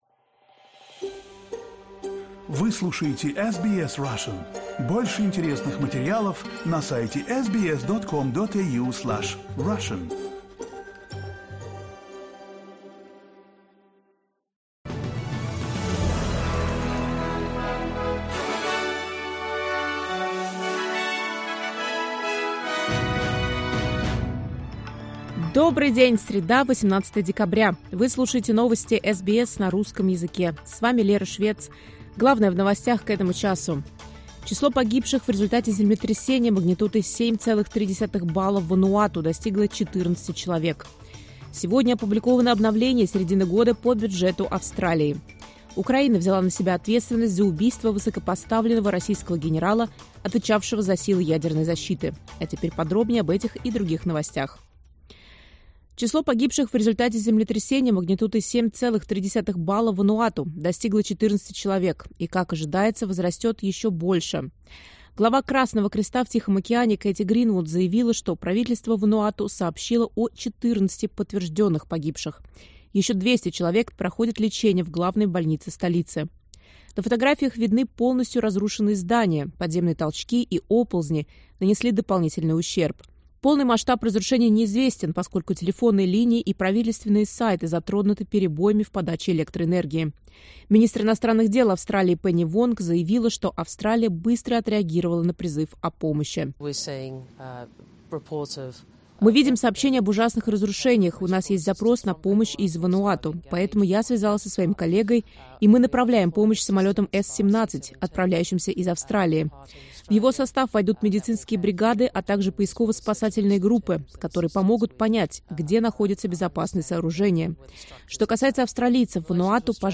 Новости SBS на русском языке — 18.12.2024